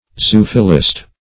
zoophilist - definition of zoophilist - synonyms, pronunciation, spelling from Free Dictionary
Search Result for " zoophilist" : The Collaborative International Dictionary of English v.0.48: Zoophilist \Zo*oph"i*list\, n. [Zoo- + Gr.
zoophilist.mp3